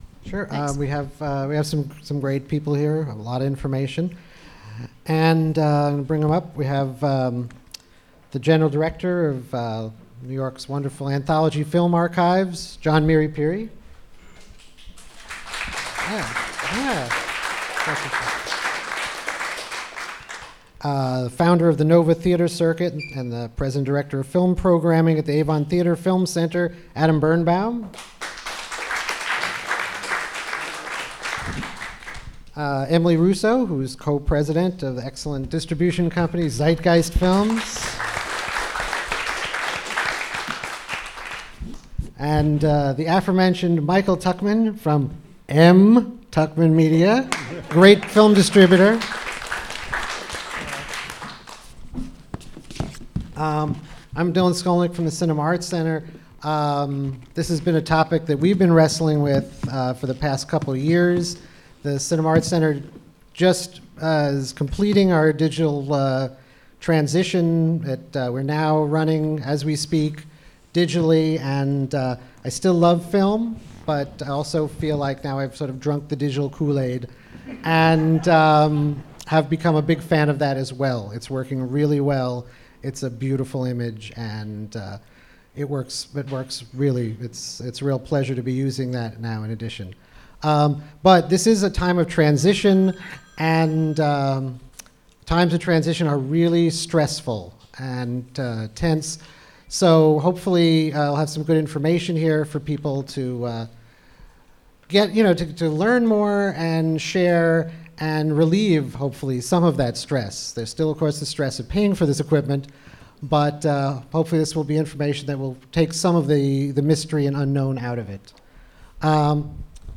Elinor Bunin Monroe Film Center